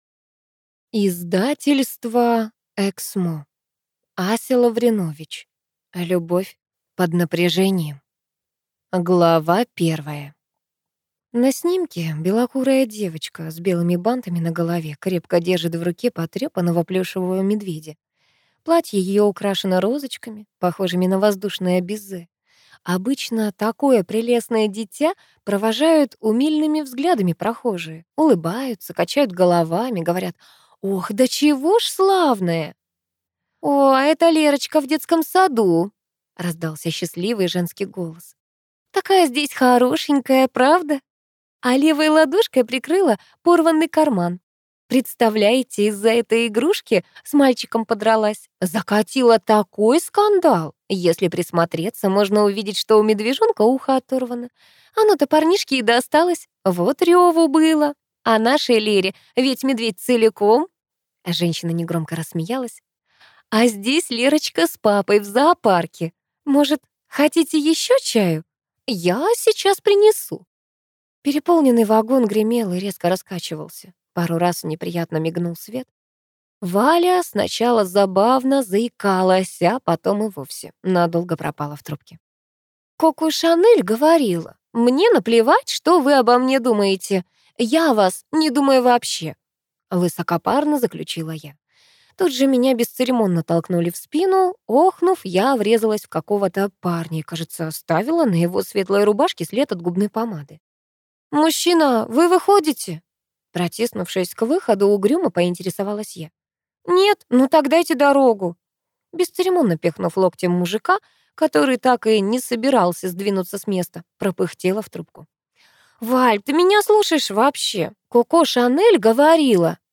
Аудиокнига Любовь под напряжением | Библиотека аудиокниг